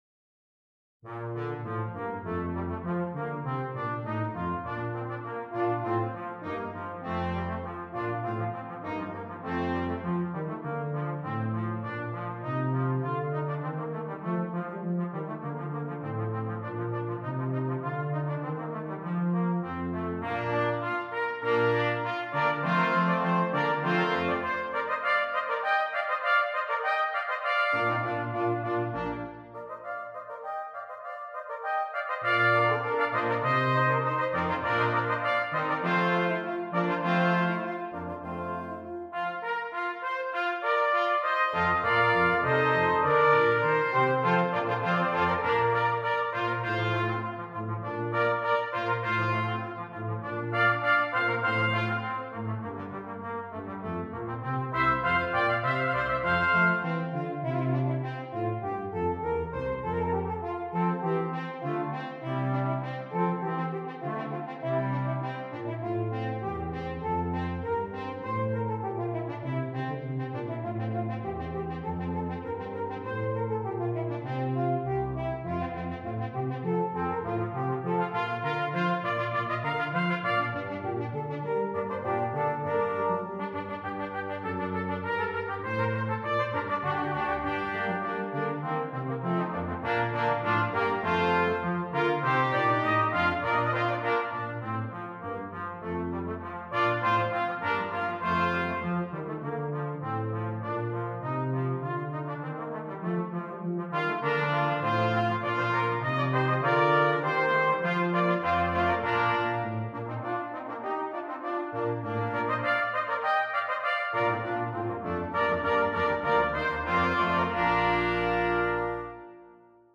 Brass Quintet
The key has been transposed from Eb to Bb concert.